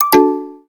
deactivate.ogg